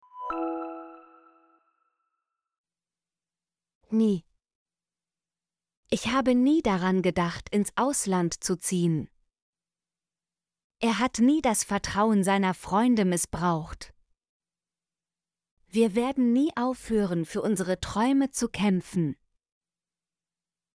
nie - Example Sentences & Pronunciation, German Frequency List